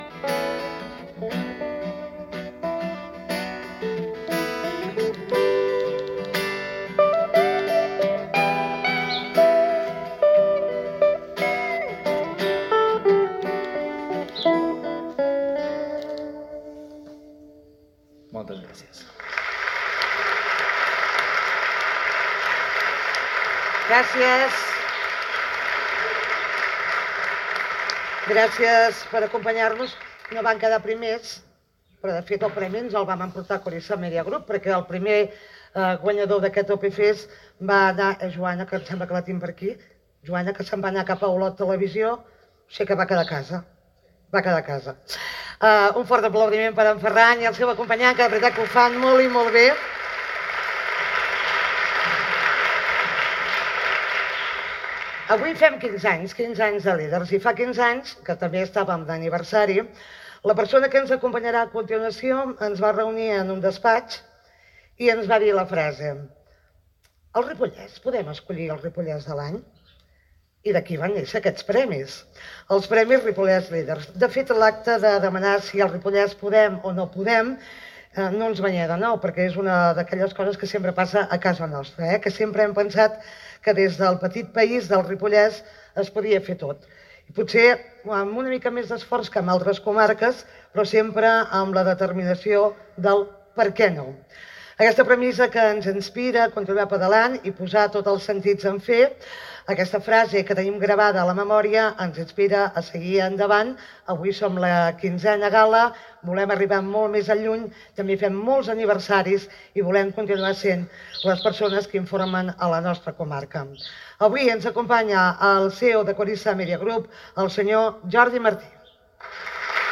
Transmissió de la gala dels Premis Ripollès Líder 2024.